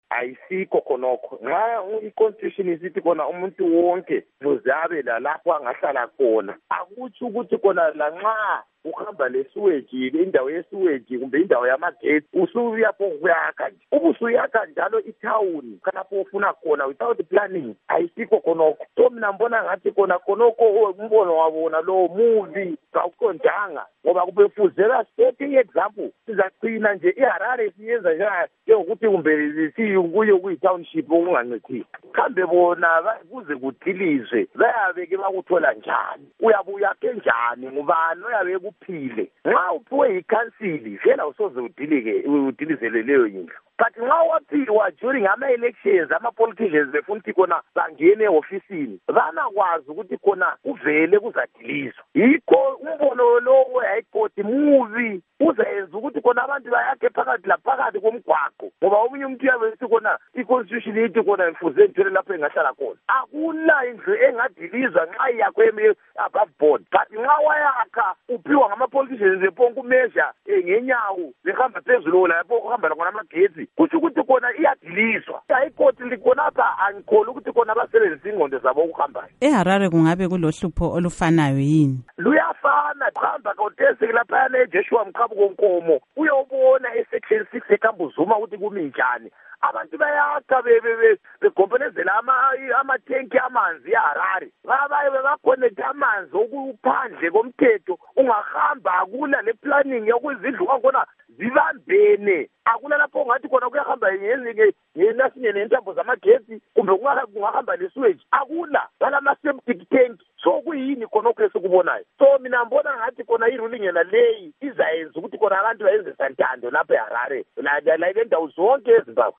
Ingxoxo LoKhansila Peter Moyo